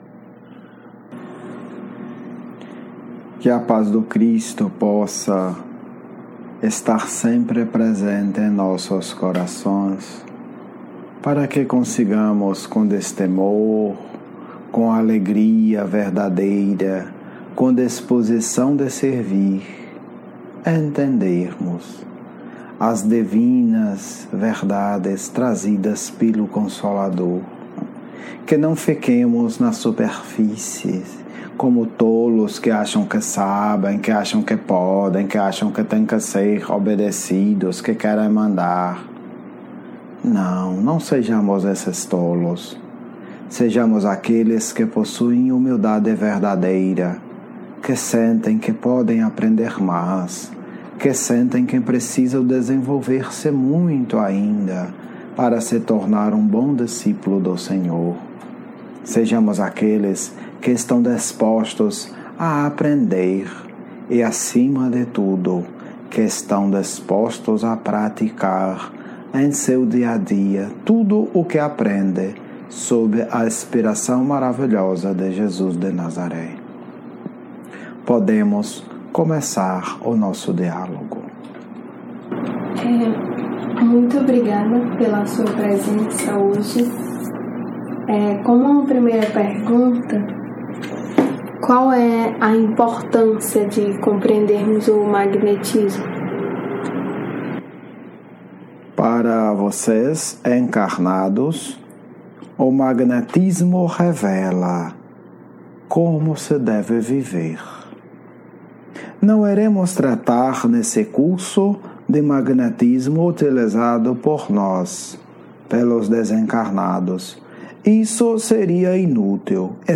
Estudo 1 – Magnetismo e universo - Diálogo mediúnico 2
magnetismo-1-dialogo-mediunico-2.mp3